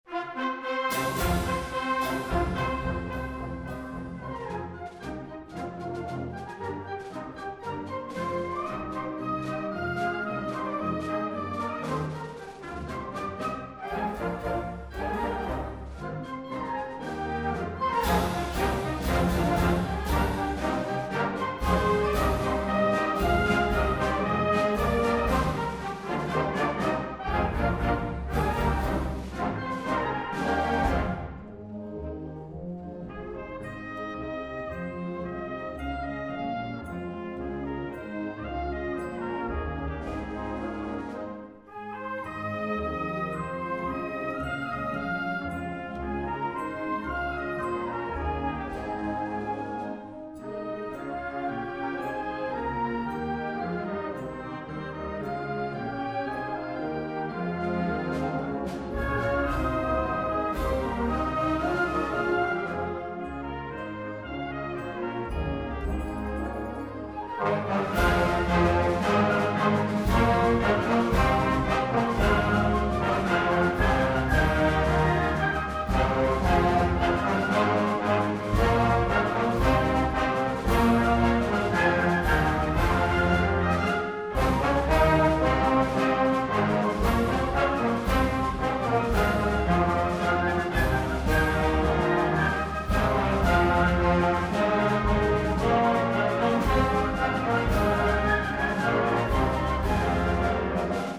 (Senior Concert Band)